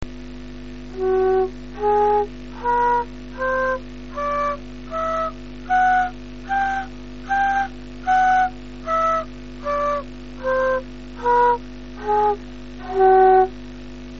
横笛一号
庭の竹(黒竹)を使って横笛を作ってみました。
適当に切ったので音程はデタラメです。
開管の長さが400mmで、共鳴周波数は380Hzでした。
この音をドとして、音程を刻んでます。
音域は一オクターブです。
音程が合わず、穴を開け直してたりしてますが、音程の誤差は2%程度におさまりました。
リコーダの横笛版に近いものです。